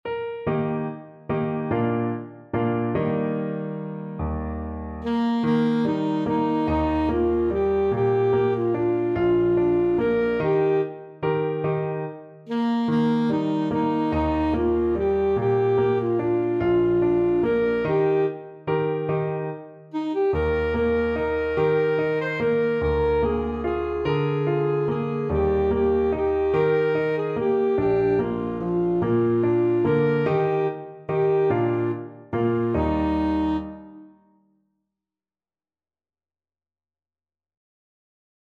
Alto Saxophone
Eb major (Sounding Pitch) C major (Alto Saxophone in Eb) (View more Eb major Music for Saxophone )
3/4 (View more 3/4 Music)
Bb4-C6
One in a bar =c.145
Traditional (View more Traditional Saxophone Music)